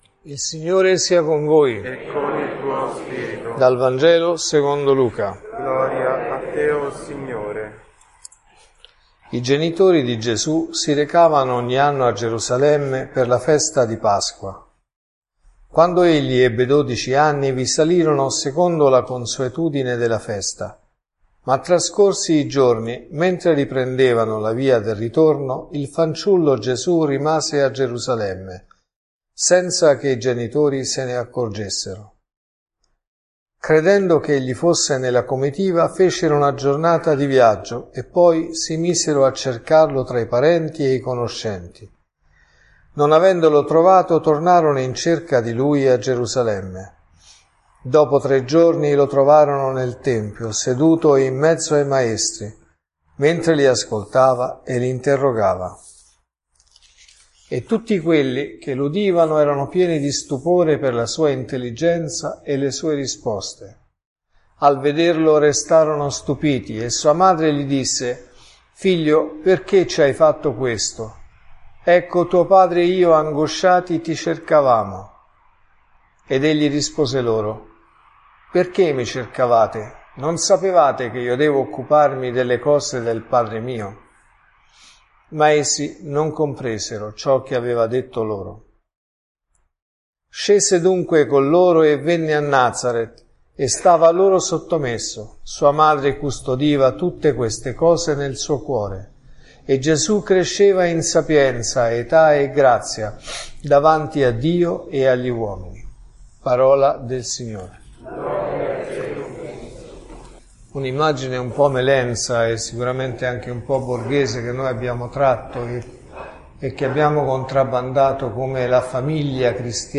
Gesù è ritrovato dai genitori nel tempio in mezzo ai maestri.(Messa del Mattino e Sera)